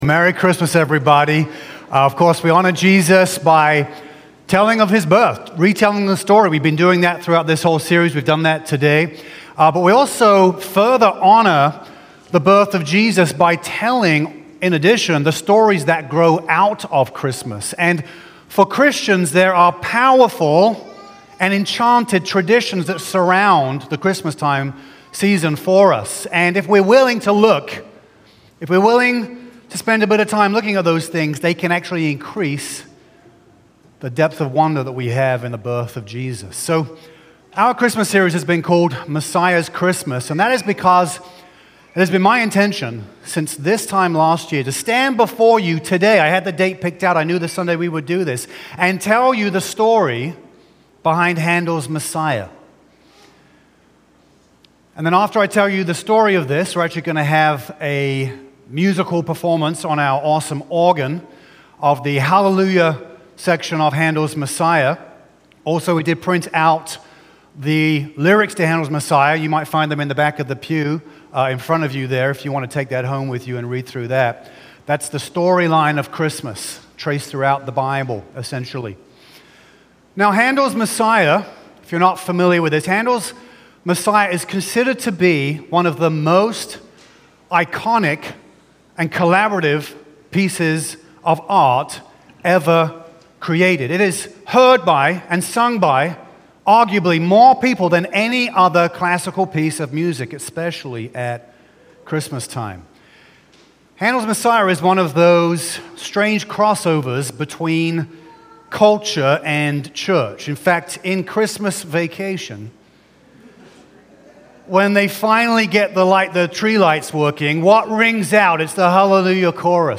A message from the series "Messiah's Christmas."